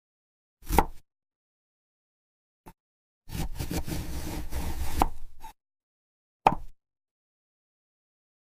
Mythical Mushroom Cutting ASMR from